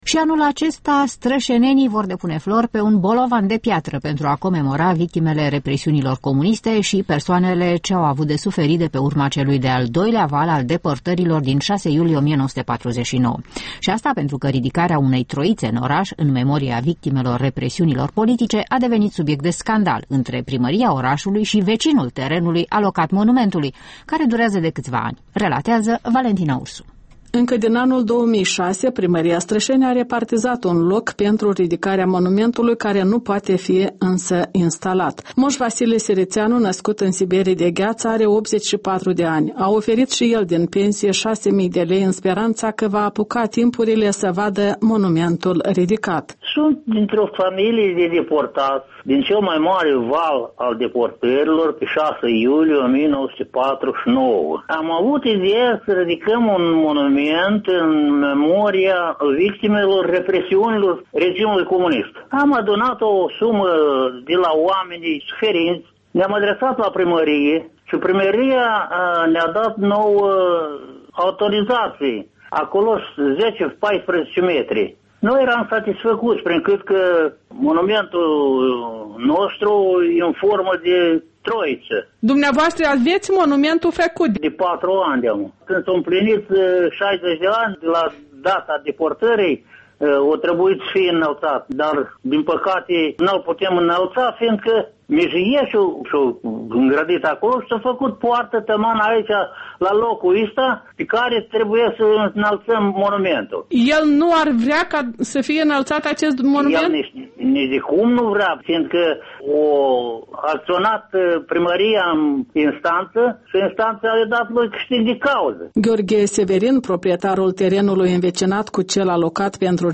Soarta unui monument al deportaților: un reportaj din Strășeni